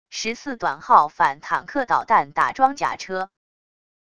14短号反坦克导弹打装甲车wav音频